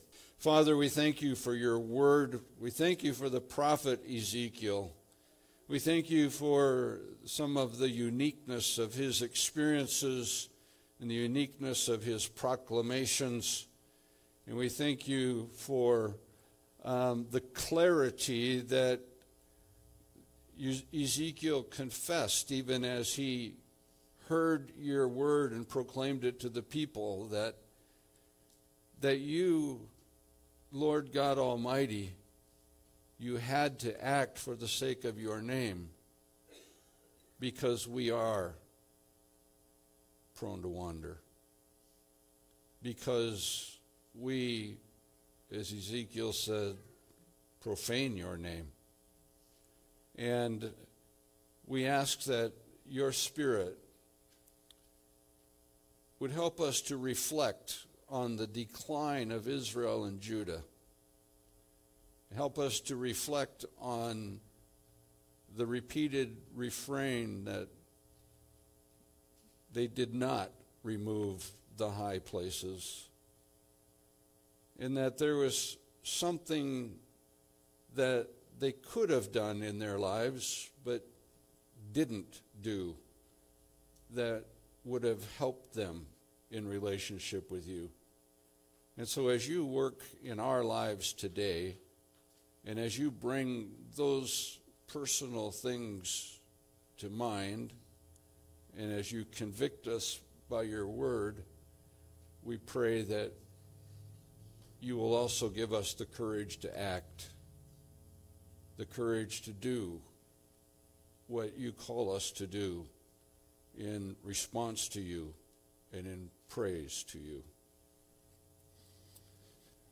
Passage: Ezekiel 10 Service Type: Sunday Service